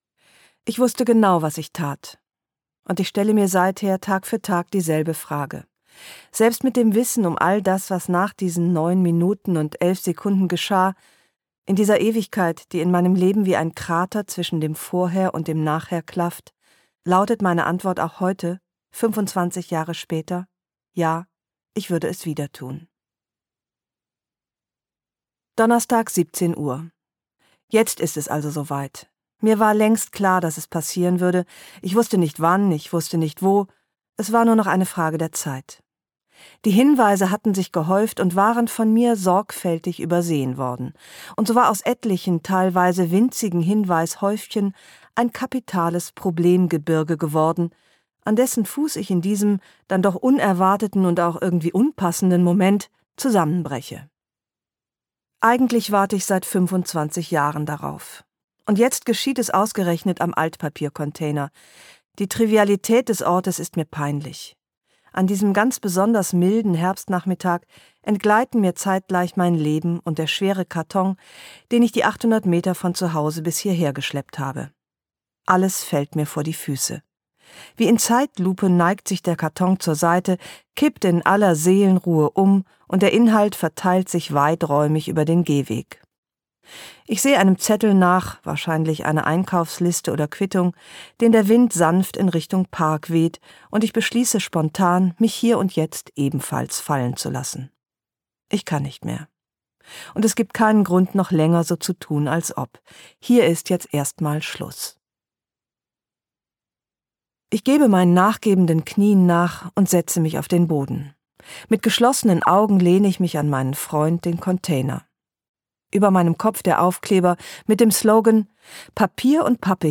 Ein warmherziges Hörbuch über die Frauen, die wir waren, die wir sind – und die wir werden wollen.
Gekürzt Autorisierte, d.h. von Autor:innen und / oder Verlagen freigegebene, bearbeitete Fassung.
Eine halbe Ewigkeit Gelesen von: Ildikó von Kürthy